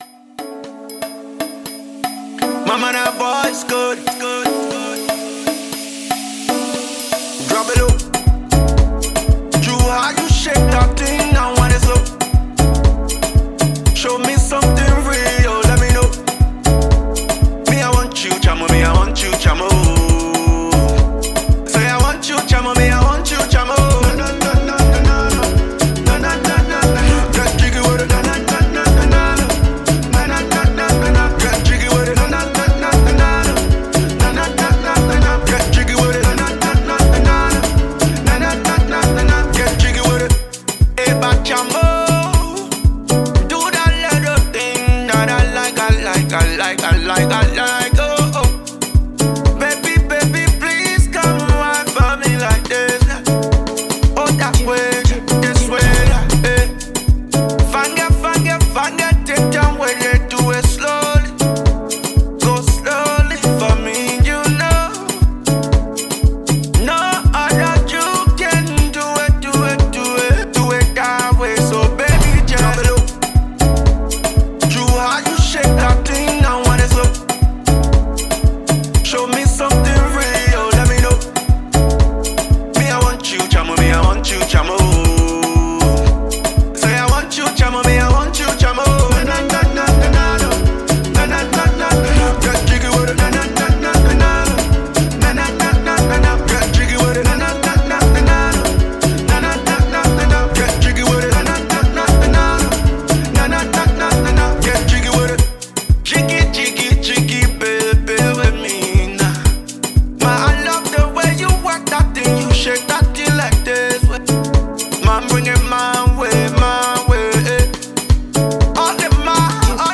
This vibe is for the club, the harmony is just catchy.